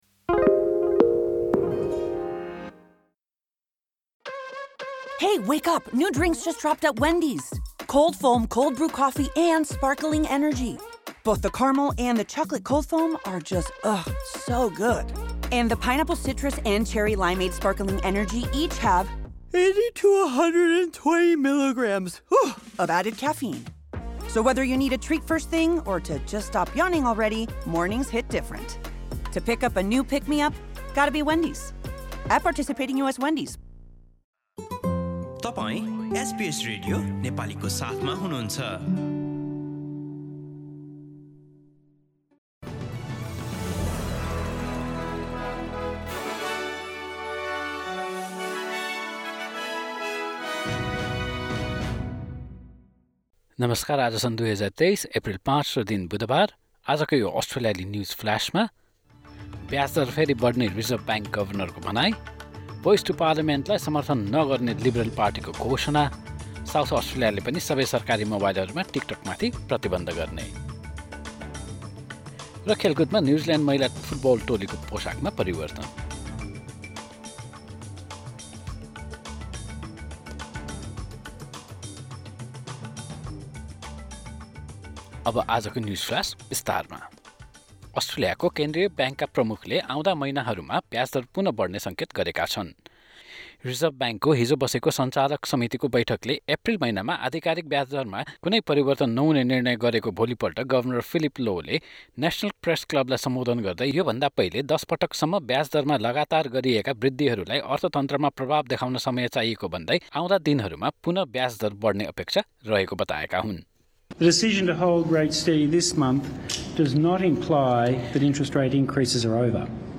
एसबीएस नेपाली अस्ट्रेलिया न्युजफ्लास: बुधवार ५ एप्रिल २०२३